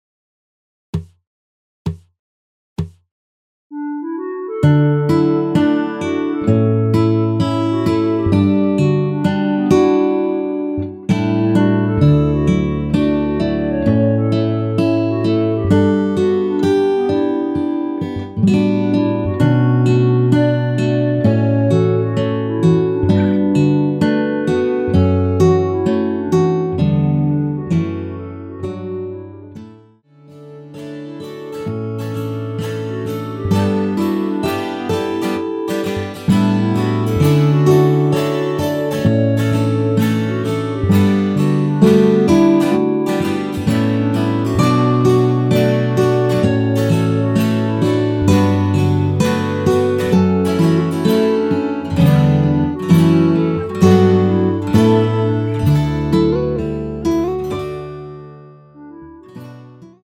(-1)내린 멜로디 포함된 MR 입니다.
◈ 곡명 옆 (-1)은 반음 내림, (+1)은 반음 올림 입니다.
앞부분30초, 뒷부분30초씩 편집해서 올려 드리고 있습니다.